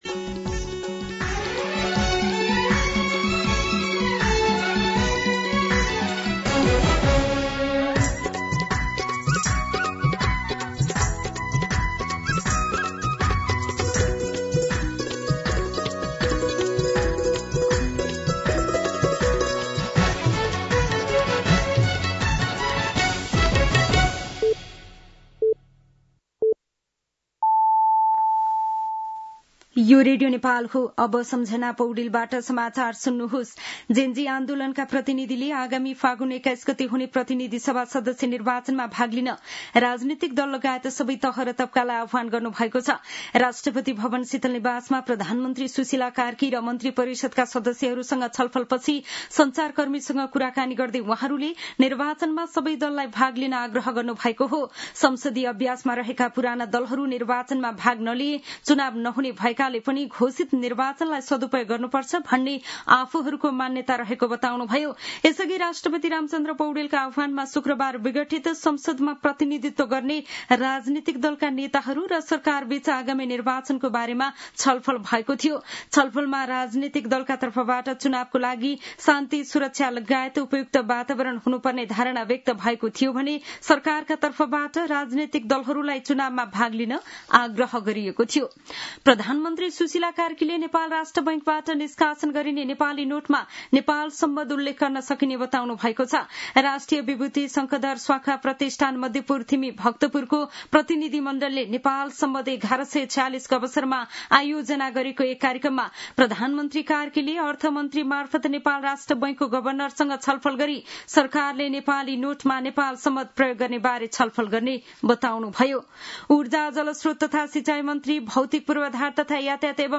दिउँसो १ बजेको नेपाली समाचार : २४ मंसिर , २०८२
1-pm-Nepali-News-5.mp3